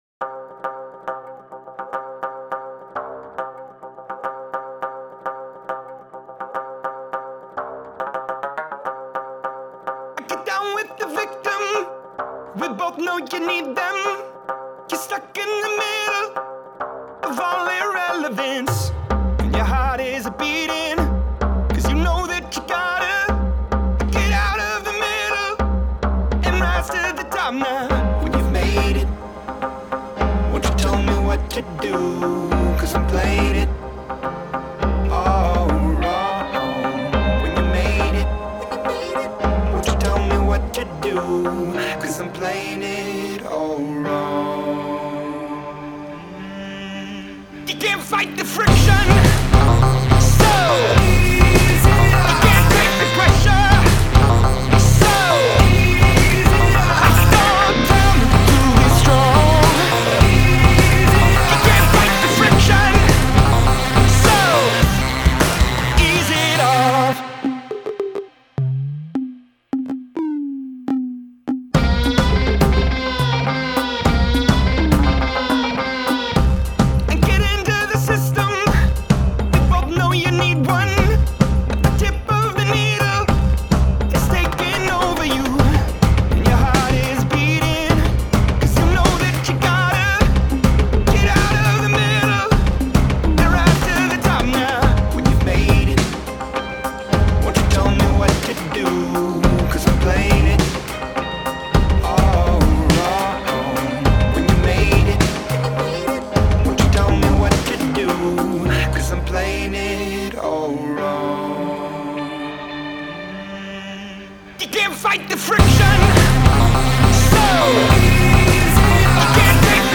Genres: Alternative, Pop Release Date